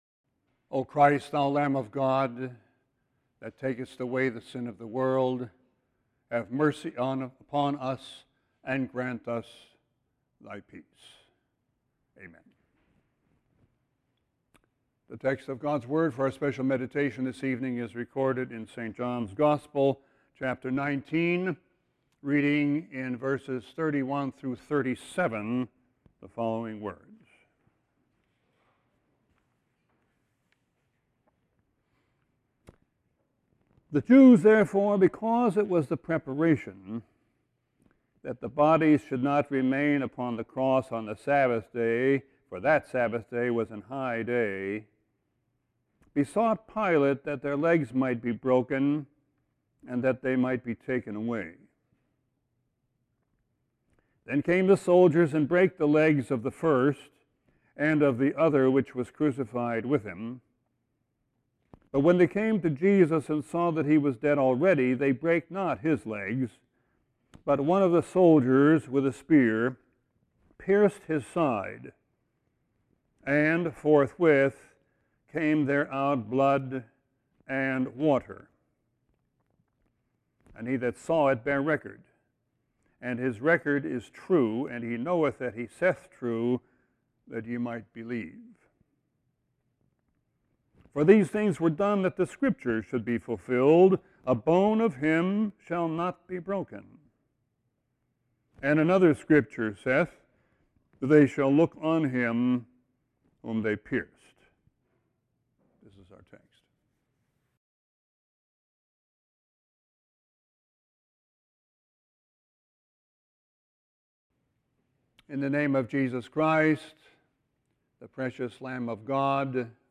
Sermon 4-5-17.mp3